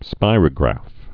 (spīrə-grăf)